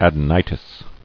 [ad·e·ni·tis]